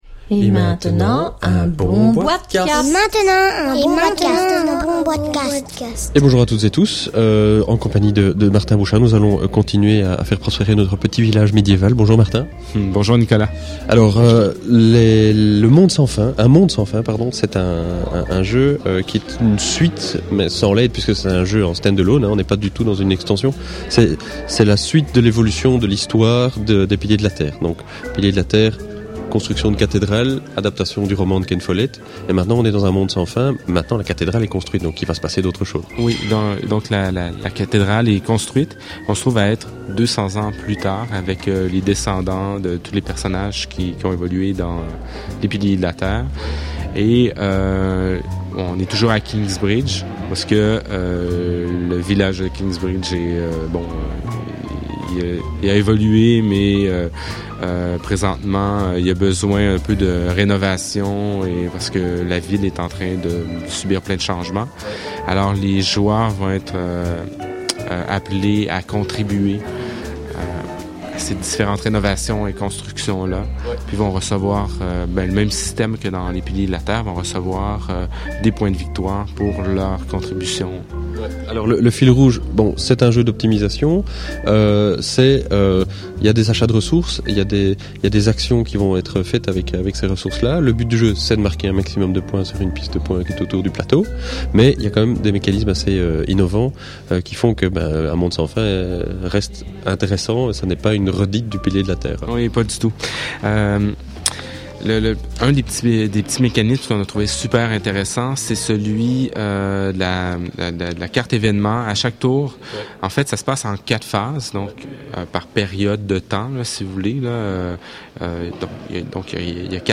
(enregistré lors du salon international de la Nuremberg Toy Fair 2010)